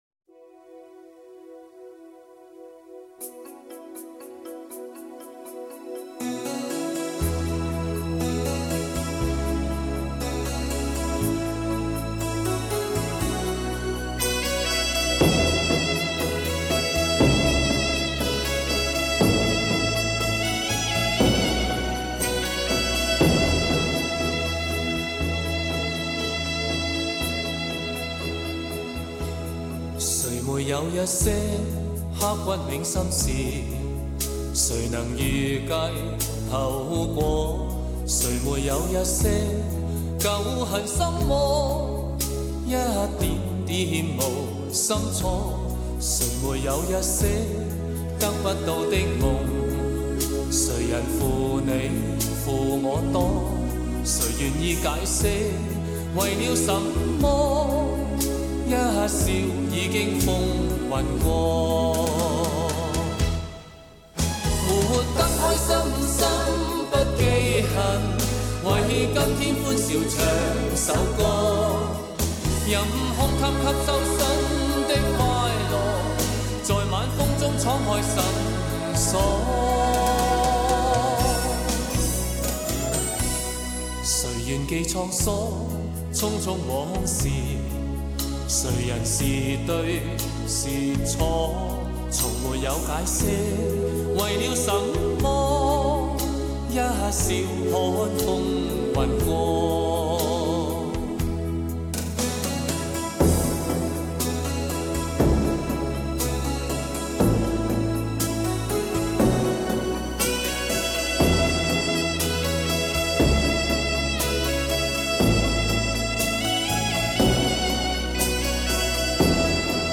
'已收藏' : '收藏'}} 4/4 61-80 中级 动态鼓谱 粤语 经典歌曲